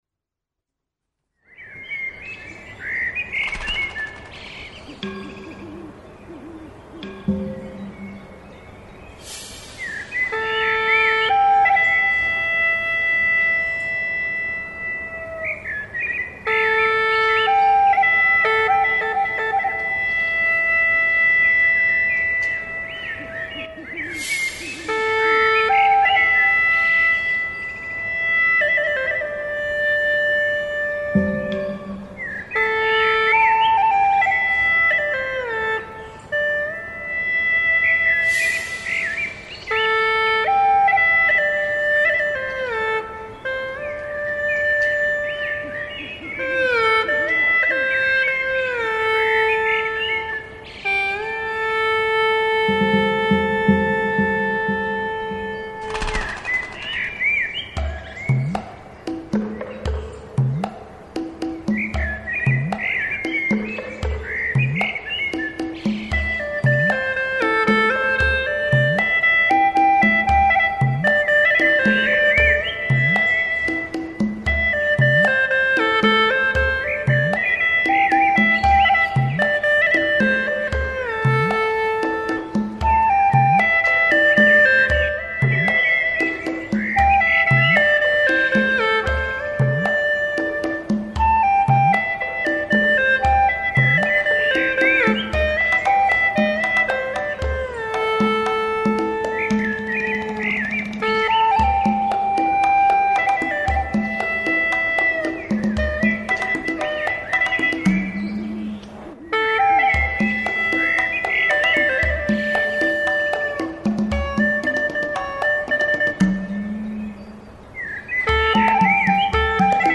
调式 : C 曲类 : 独奏